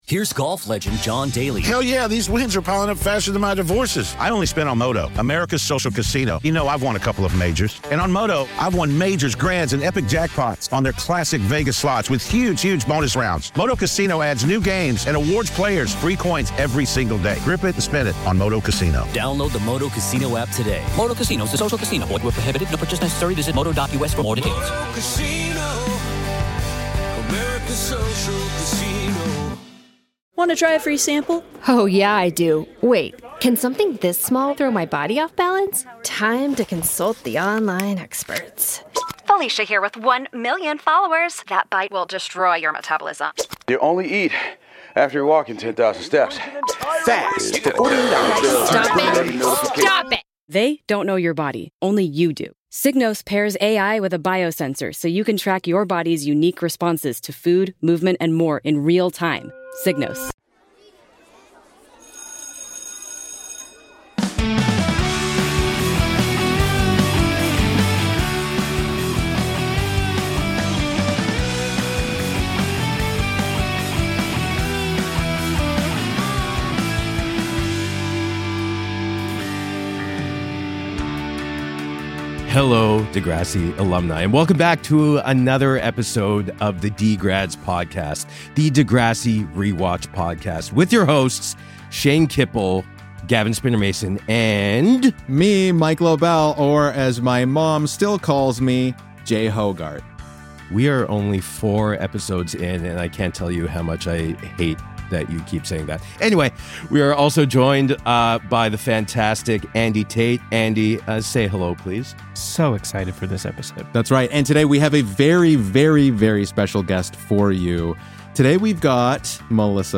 Melissa McIntyre (Ashley) joins us in this episode of DeGrads. This week, we're discussing episode 1x04 of Degrassi: The Next Generation titled Eye of the Beholder.